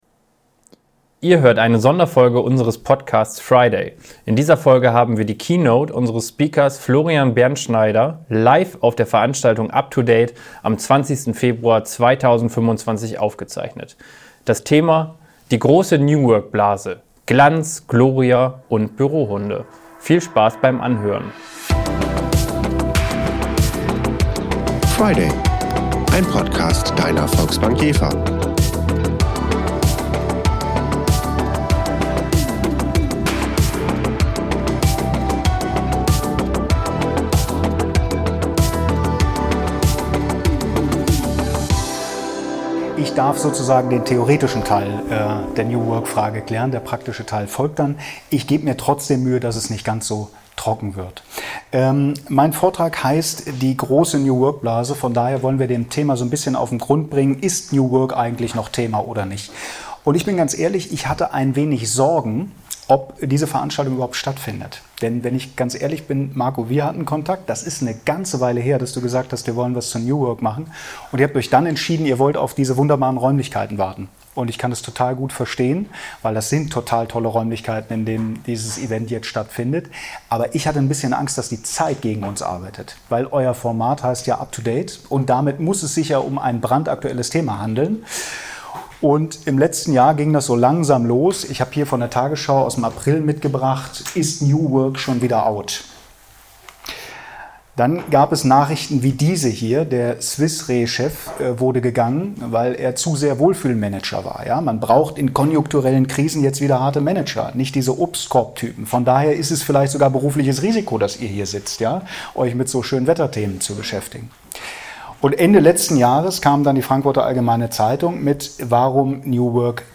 Er geht der Frage nach, ob Unternehmen mit einer 4-Tage-Woche, Kickertischen und Bürohunden tatsächlich nachhaltige Veränderungen schaffen oder ob es tiefgreifendere Anpassungen braucht. Ein weiteres zentrales Thema seines Vortrags ist der Einfluss der Generation Z auf den Arbeitsmarkt: Wird diese Generation in wirtschaftlich herausfordernden Zeiten pragmatischer agieren als bisher vermutet? Diese VRiday-Episode wurde im Rahmen der Up2Date-Veranstaltungsreihe live vor Publikum aufgezeichnet.